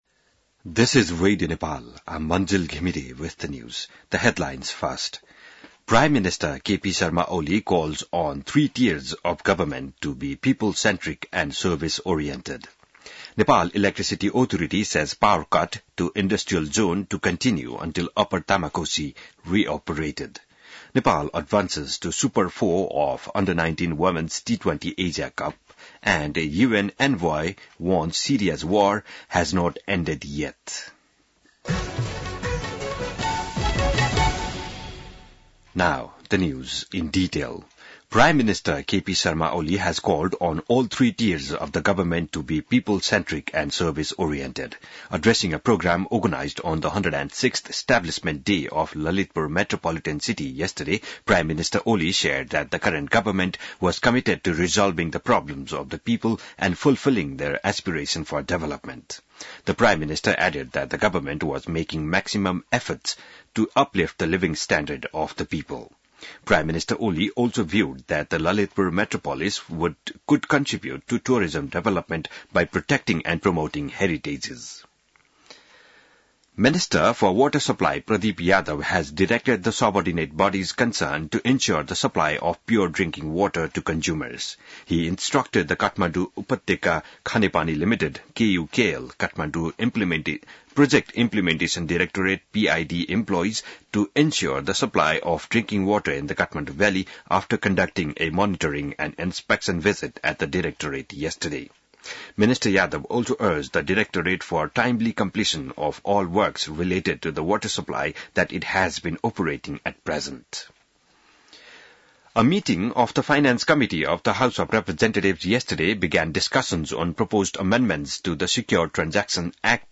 बिहान ८ बजेको अङ्ग्रेजी समाचार : ४ पुष , २०८१